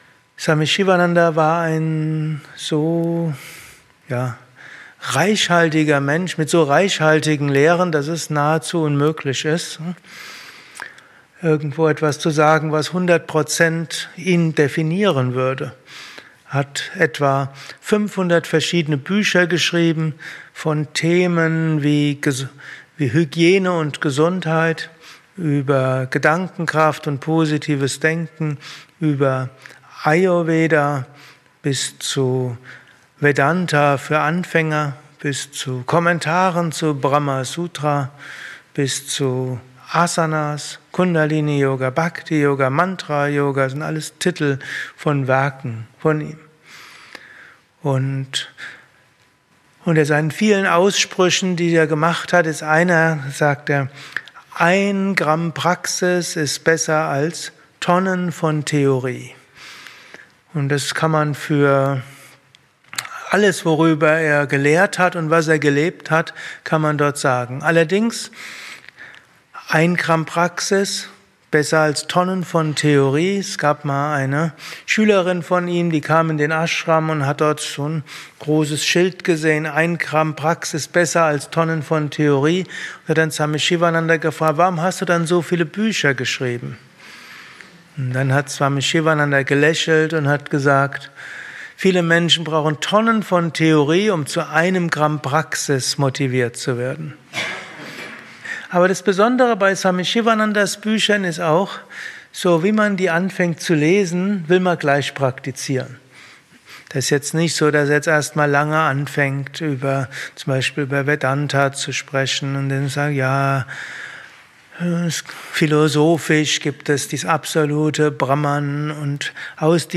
Kurzvorträge
eine Aufnahme während eines Satsangs gehalten nach einer